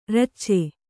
♪ racce